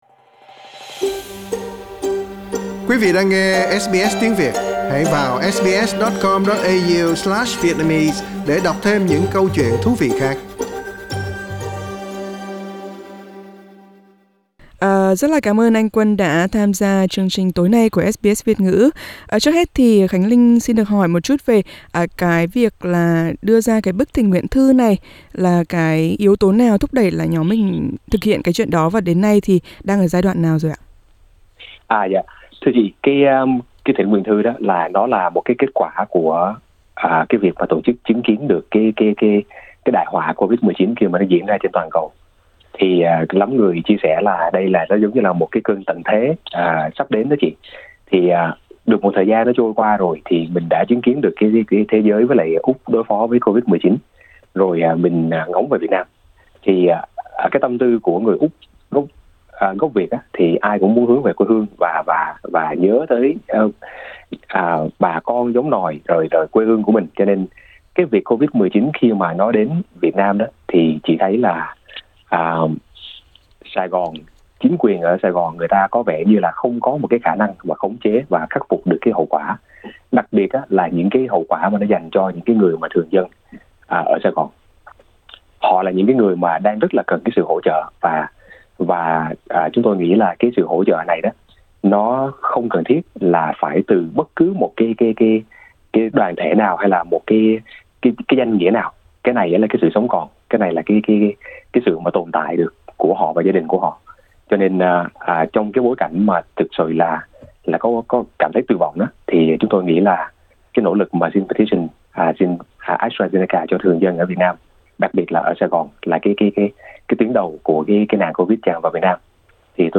SBS trò chuyện với anh